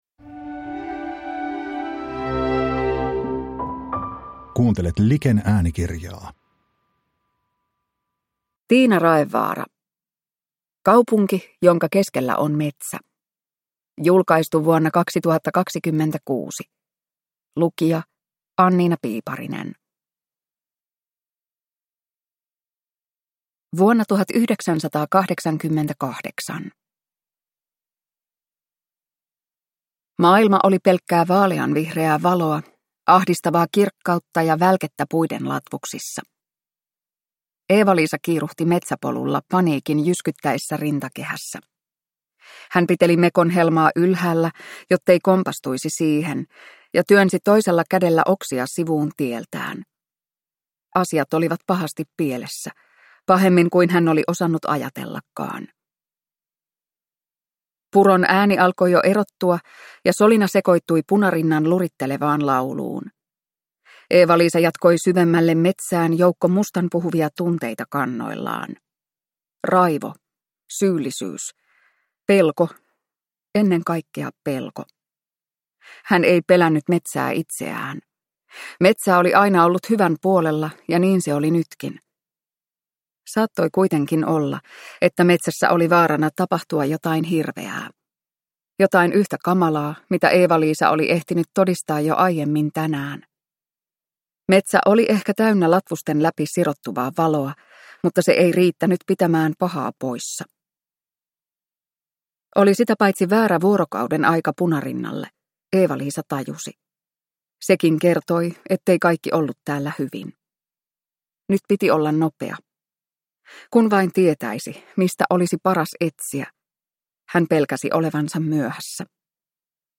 Kaupunki jonka keskellä on metsä – Ljudbok